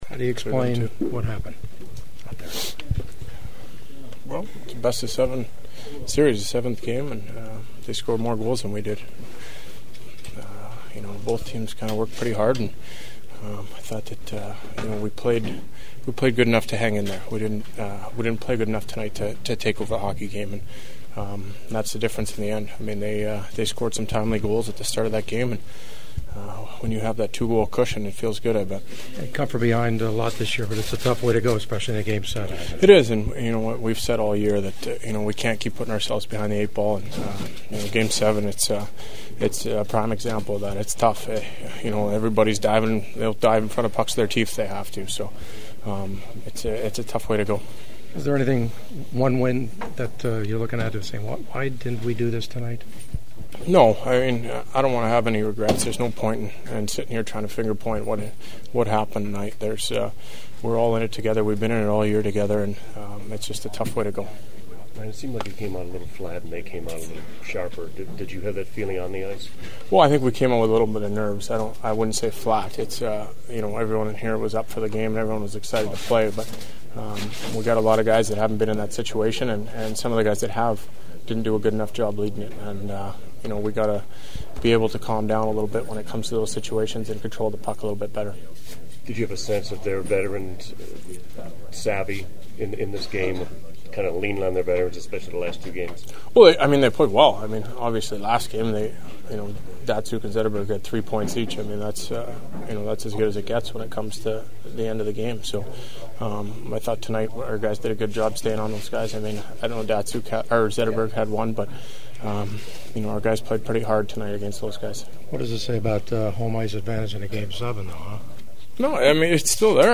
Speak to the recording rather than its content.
The Ducks had an NHL best 15 come from behind wins during the regular season but their magic also ran out something that was one of my themes of questioning in the postgame locker room.